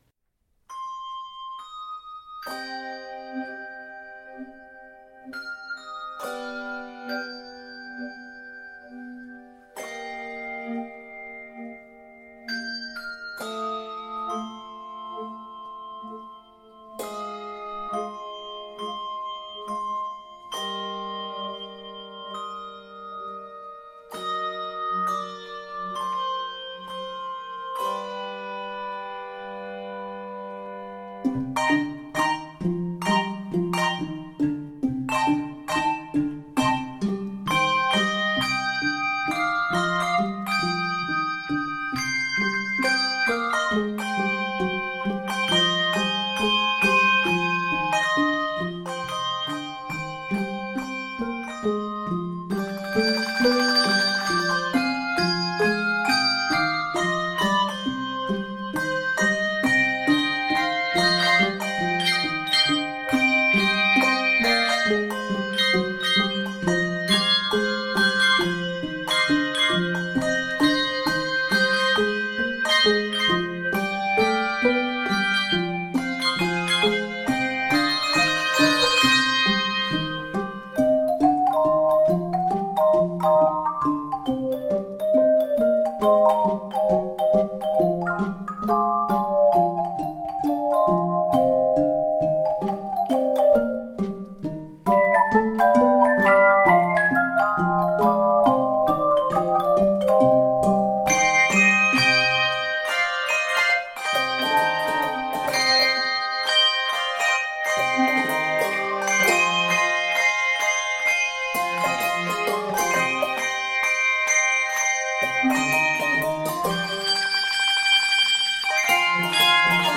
Key of c minor.